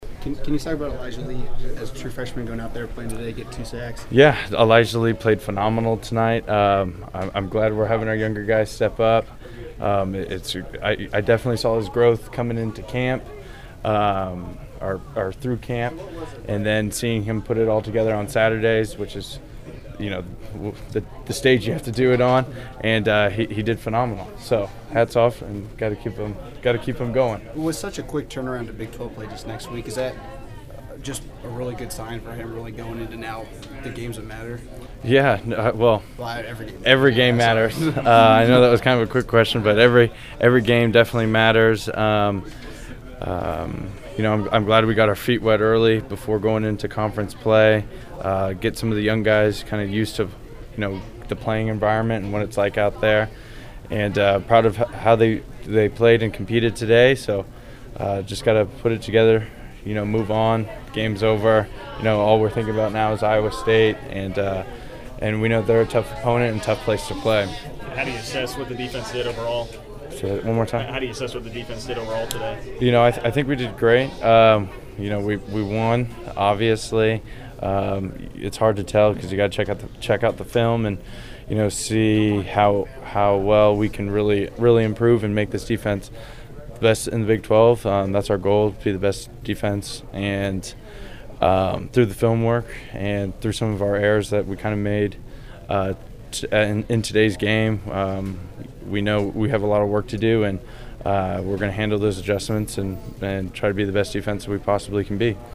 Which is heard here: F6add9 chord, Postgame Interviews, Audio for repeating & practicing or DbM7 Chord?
Postgame Interviews